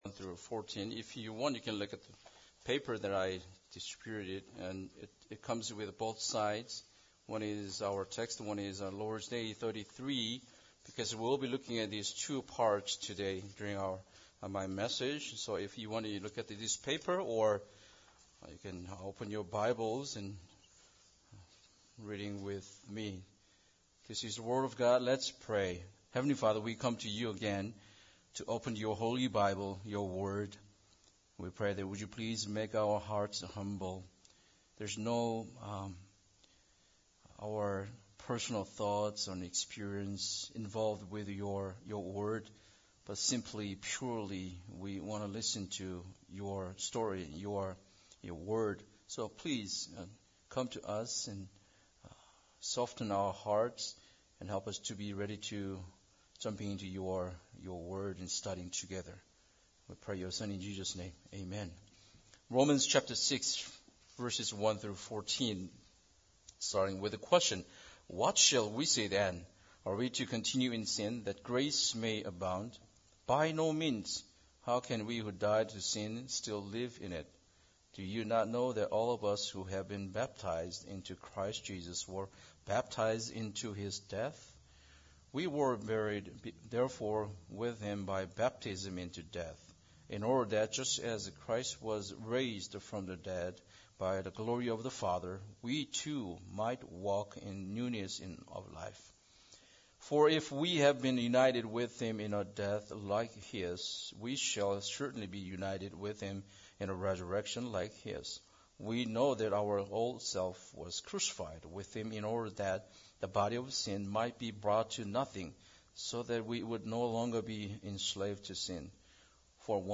Romans 6:1-14 Service Type: Special Service Bible Text